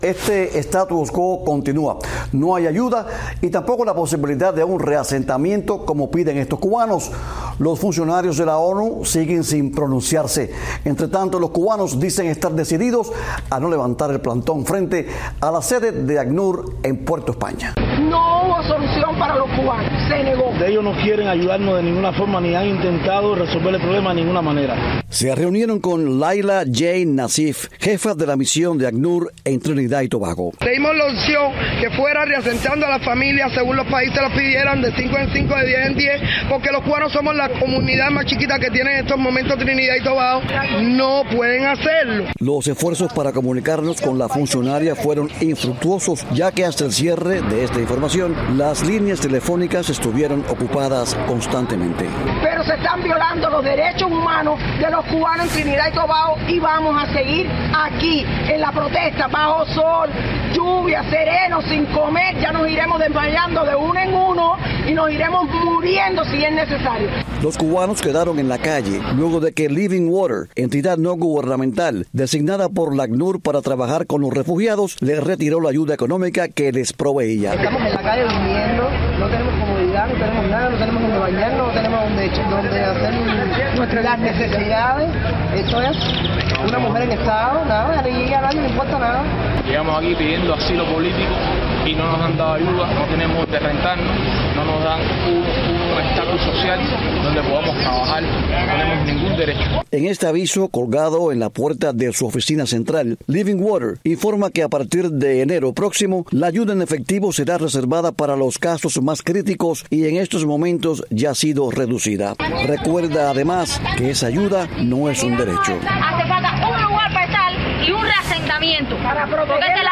Cubanos en Trinidad y Tobago, entrevistados